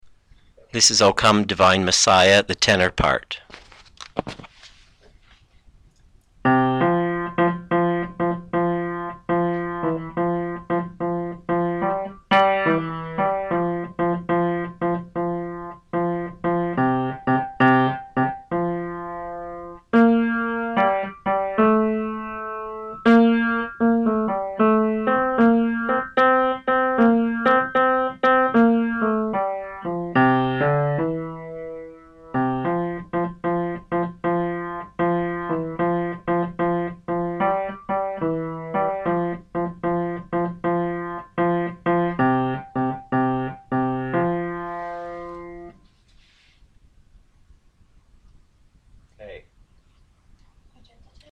O Come Divine Messiah - Tenor 2012-10-20 Choir